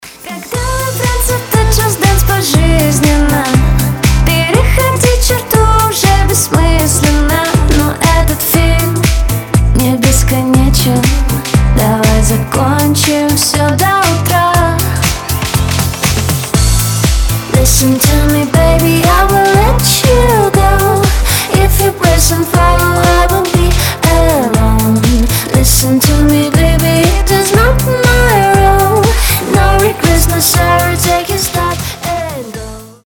• Качество: 320, Stereo
женский голос
Dance Pop
house
теплые
озорные